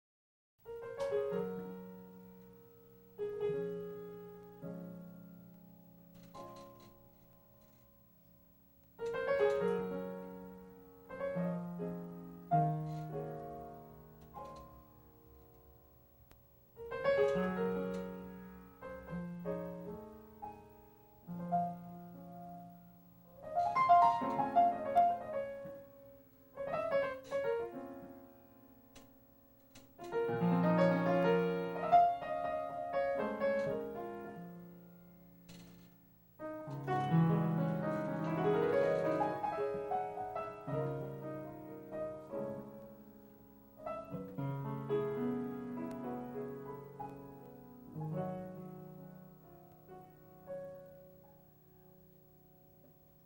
Recording: Mar.〜Sep.2000 　 at　 Holly's’ & Swing　Sing